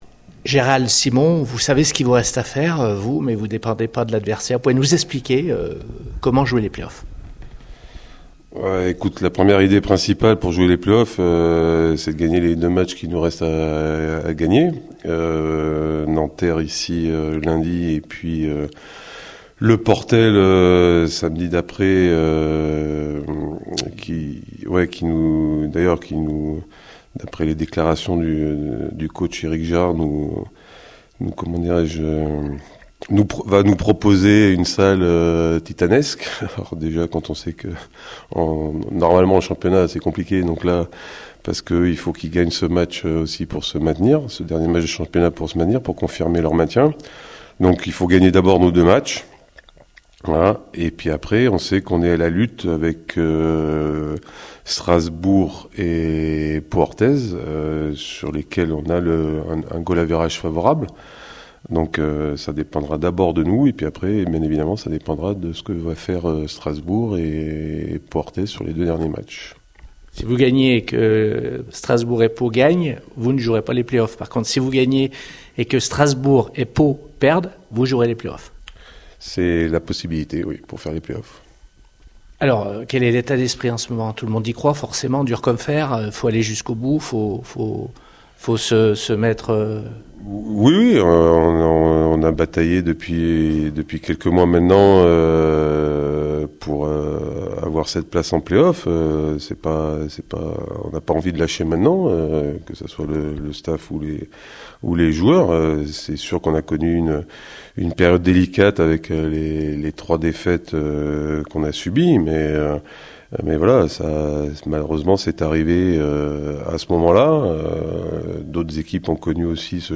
Conférence d'avant-match